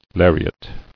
[lar·i·at]